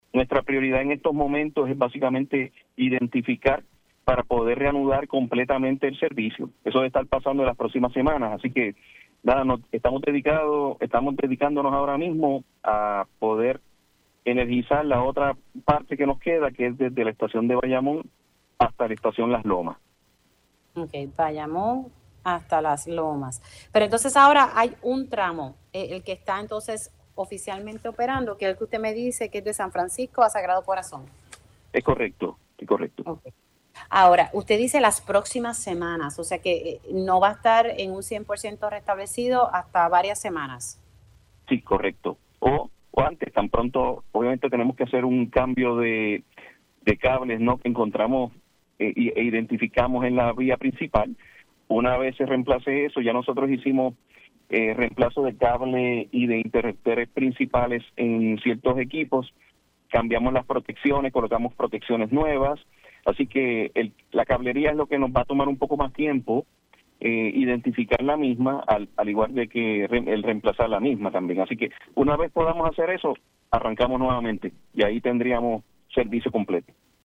El director ejecutivo de la Autoridad de Transporte Integrado (ATI), Josué Menéndez reconoció en Pega’os en la Mañana que una fluctuación de voltaje pudo haber sido la causa de la explosión en la subestación del Tren Urbano, ocurrida el pasado miércoles, 14 de mayo.